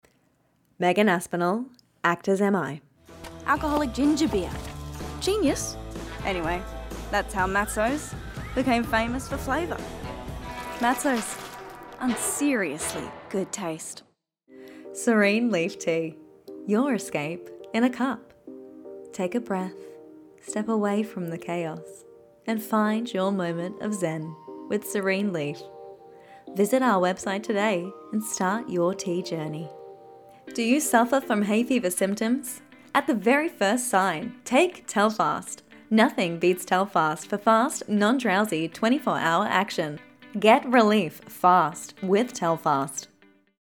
Voice Reel Demo